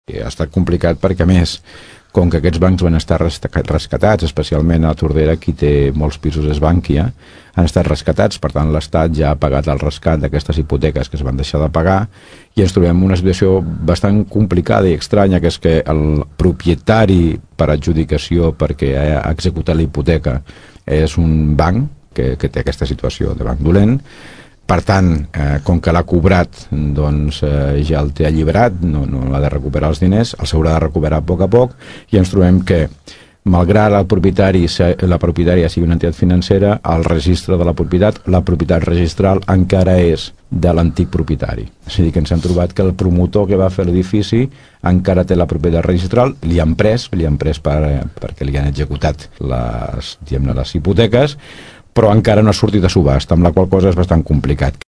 En el cas del Sareb (el banc dolent), la qüestió és més complicada, perquè aquest banc és propietari d’una gran quantitat de pisos al nostre municipi. Ho explica Joan Carles Garcia, Alcalde de Tordera.